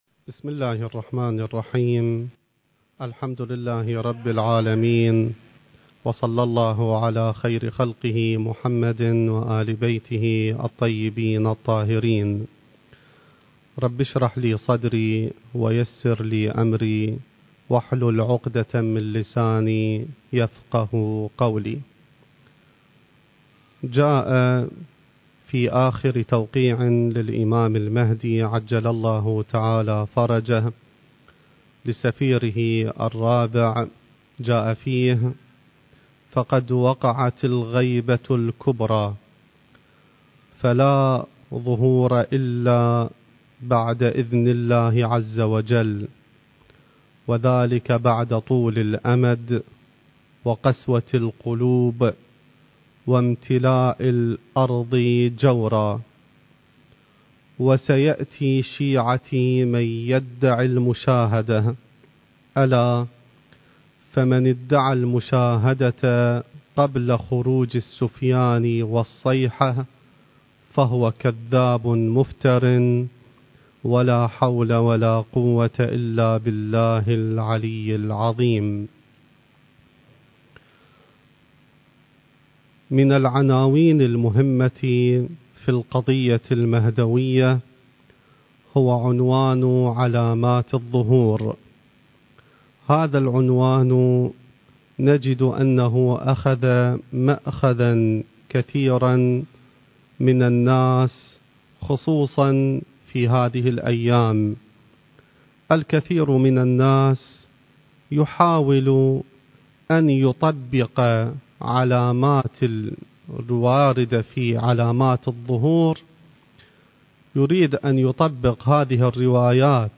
المكان: العتبة العلوية المقدسة الزمان: ذكرى ولادة الإمام المهدي (عجّل الله فرجه)